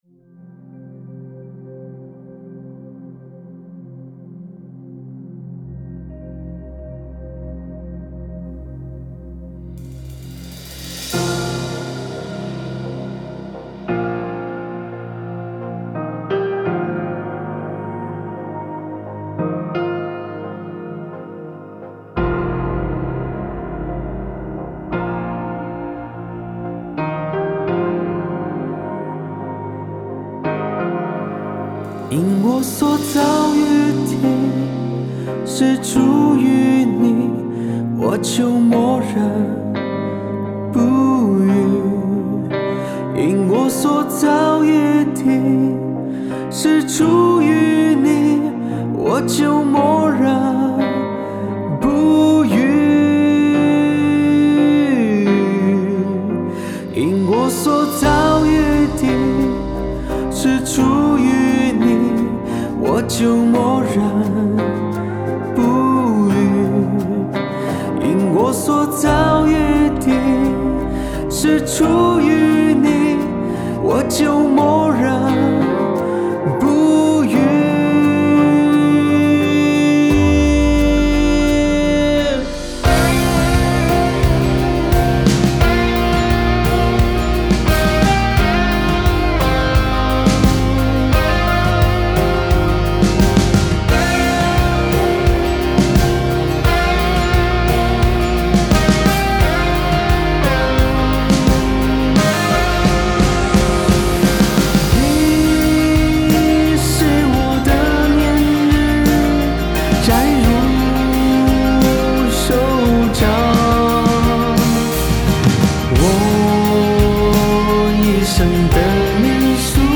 C 小調